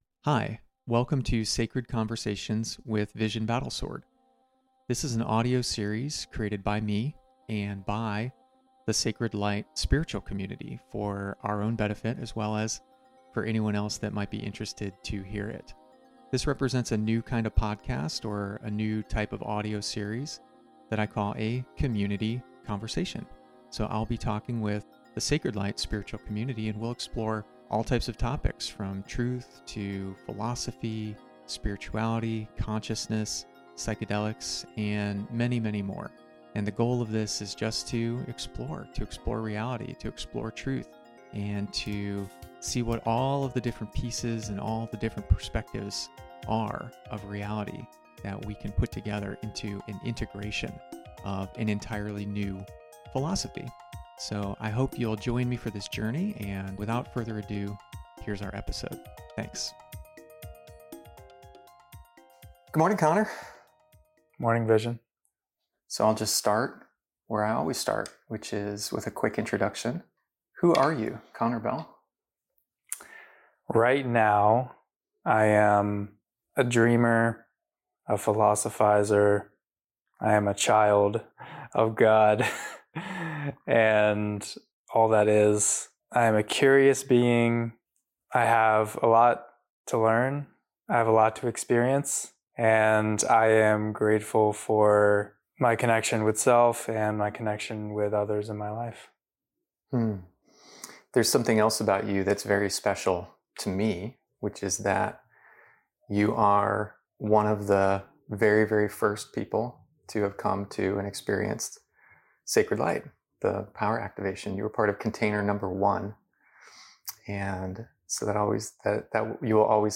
Prepare for a mind-expanding dialogue that promises to transform how you perceive and prioritize what's truly important in life.
conversation06-values.mp3